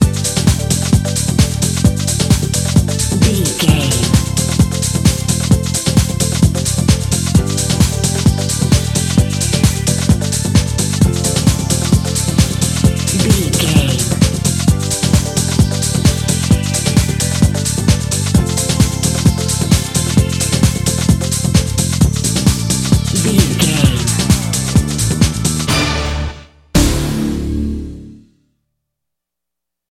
Aeolian/Minor
synthesiser
drum machine
90s